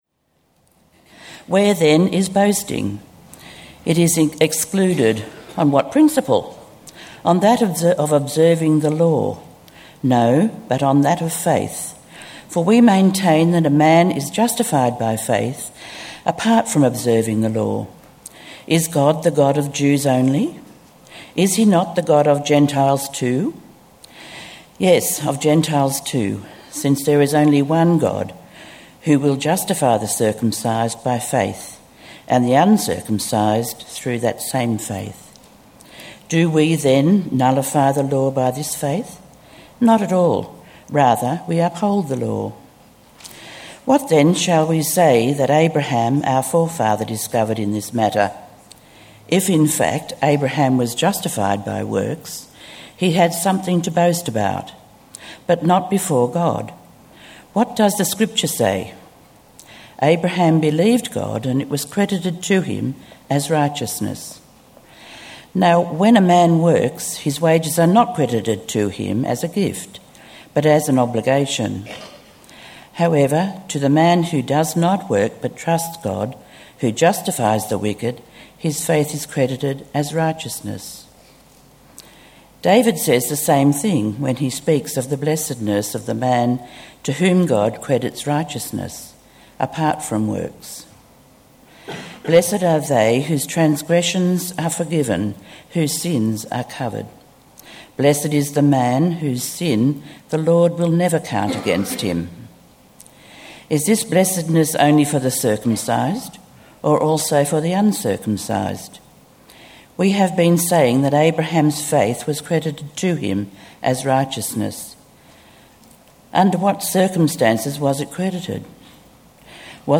Romans 3:27-4:25 Talk